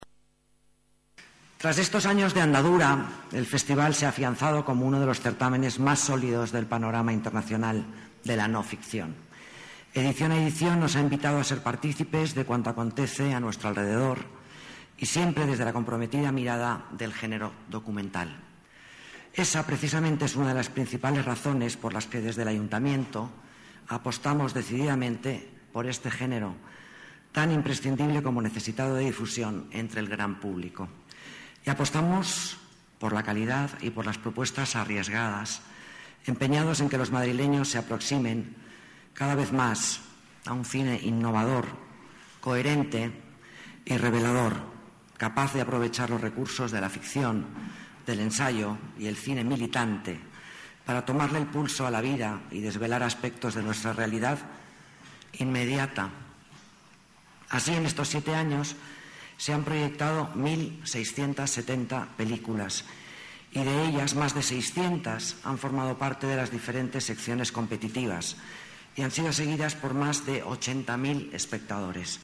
Nueva ventana:Declaraciones de Alicia Moreno, delegada de Las Artes